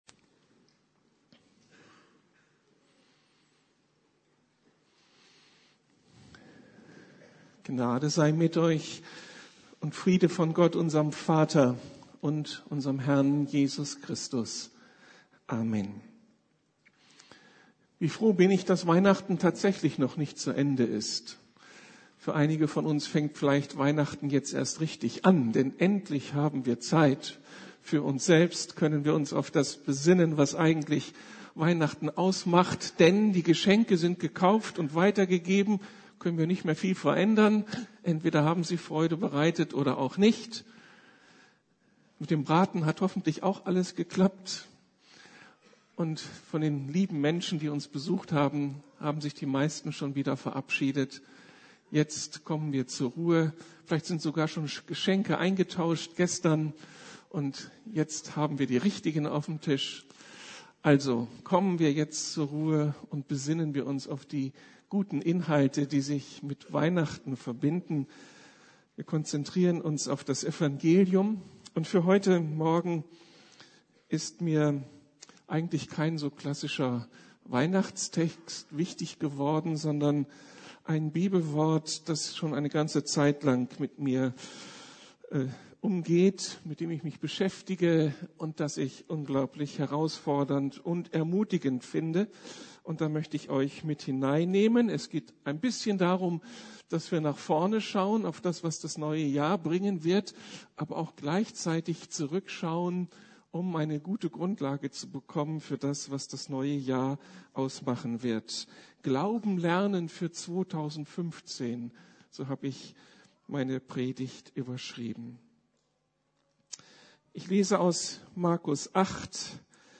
Glauben lernen 2015 ~ Predigten der LUKAS GEMEINDE Podcast